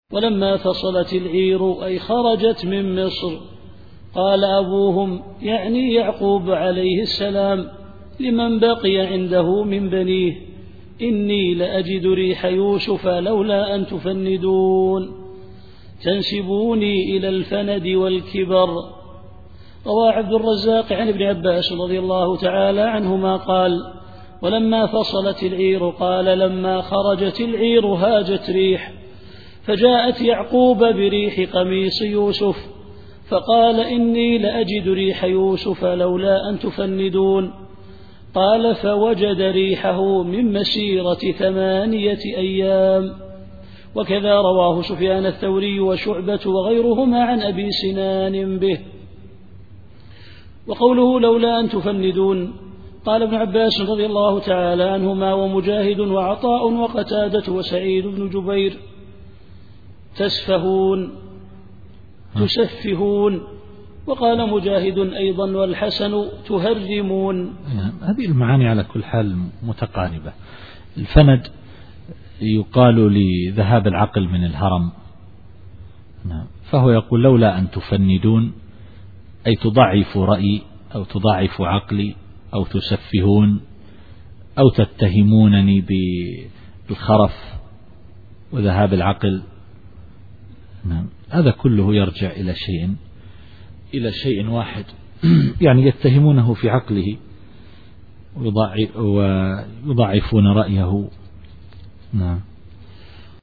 التفسير الصوتي [يوسف / 94]